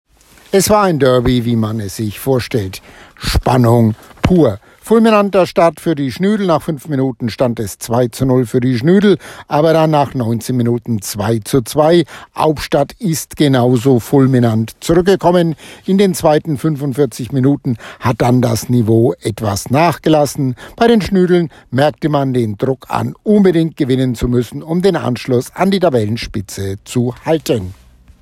Sportreporter